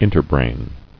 [in·ter·brain]